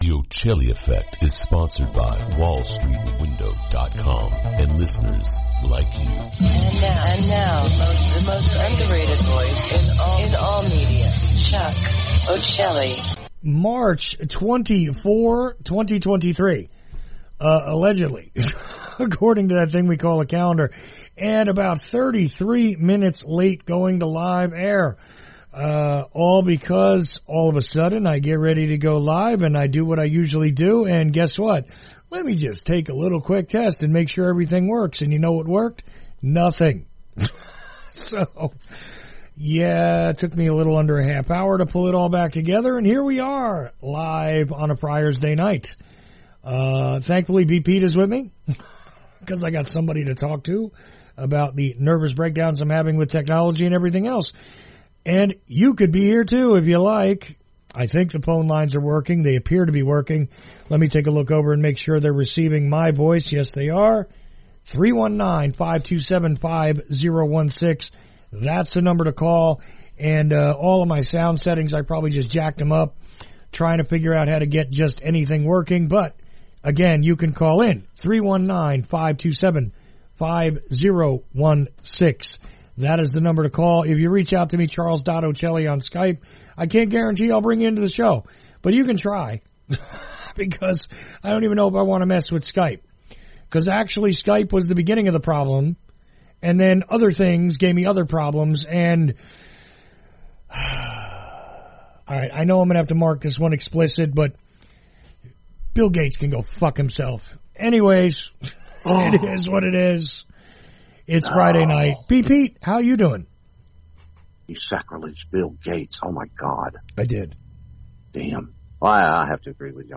Unscripted call-ins happened on the Friday Night Open Mic broadcast.